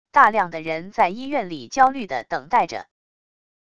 大量的人在医院里焦虑的等待着wav音频